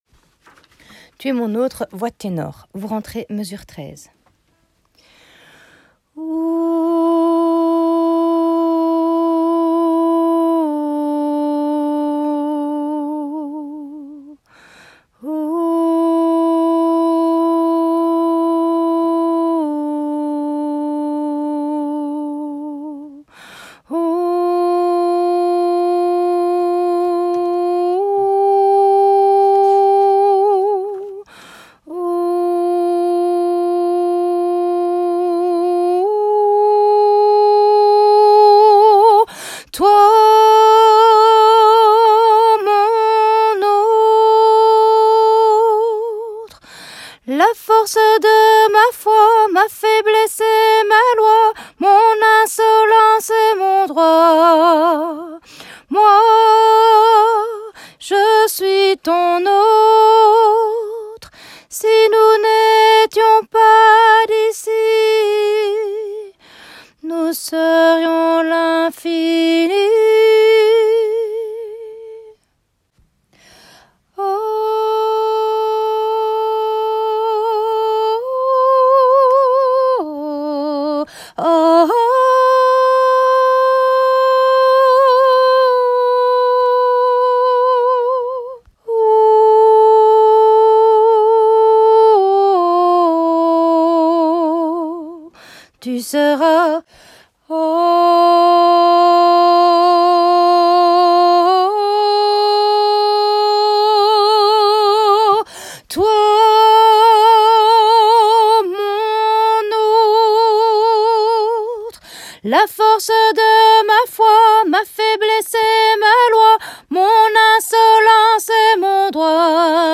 Tu-es-mon-autre-tenor.m4a